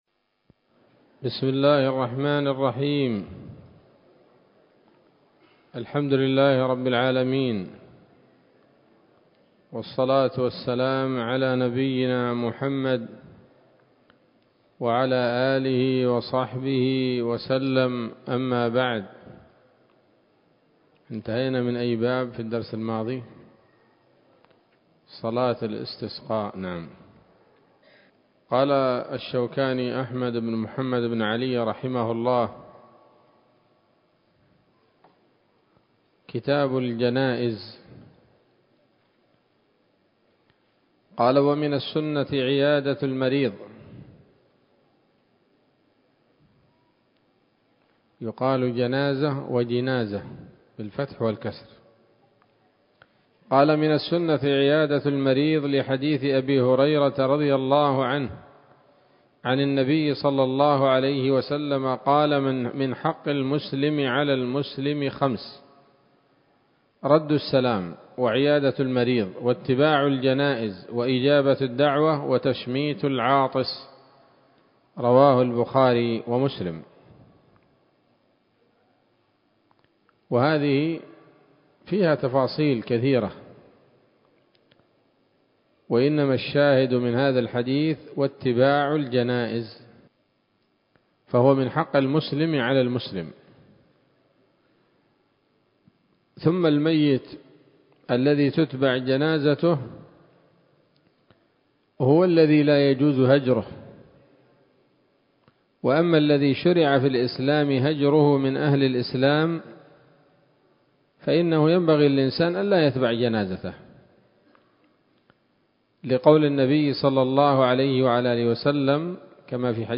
الدرس الأول من كتاب الجنائز من السموط الذهبية الحاوية للدرر البهية